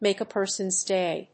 アクセントmàke a person's dáy